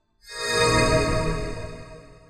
Trimmed-Healing-Spells
sfx updates